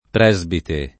presbite [ pr $@ bite ]